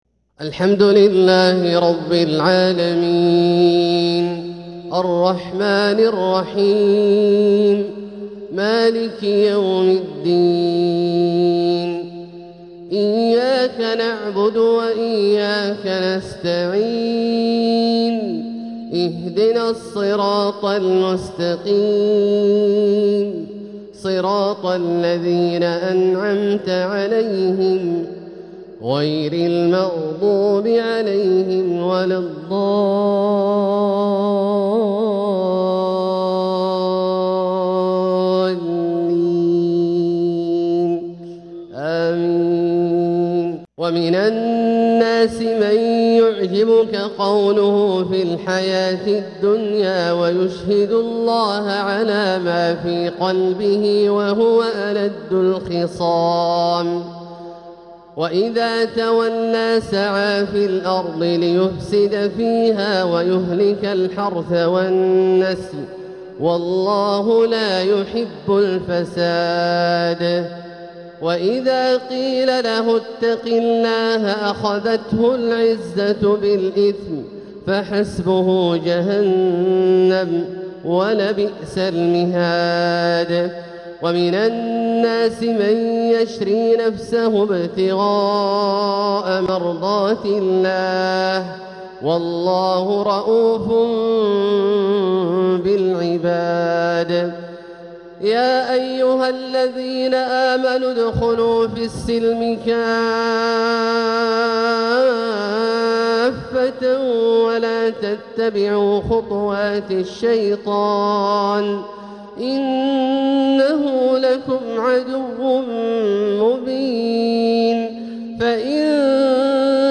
تلاوة بتحبير رائق من سورة البقرة للشيخ عبدالله الجهني | فجر الأربعاء ٩-٤-١٤٤٧ هـ > ١٤٤٧هـ > الفروض - تلاوات عبدالله الجهني